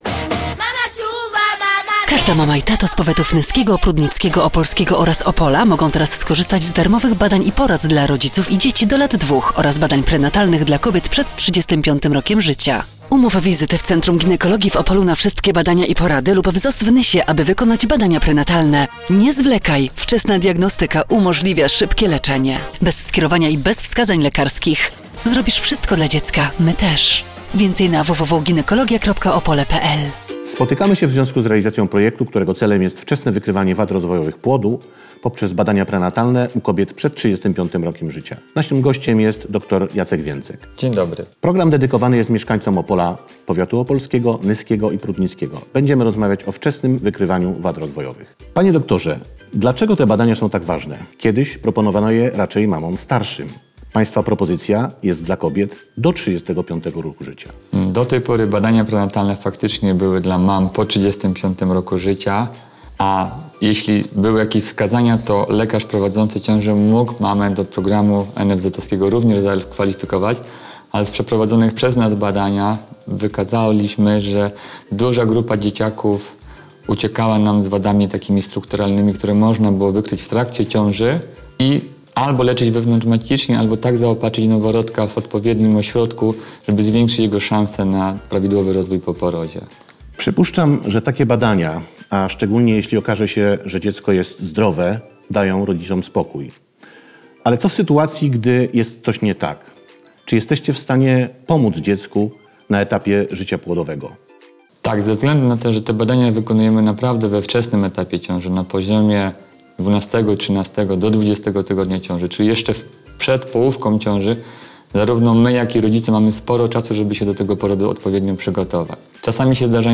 AUDYCJA_RADIOWA_NR_5_V3__1_.wav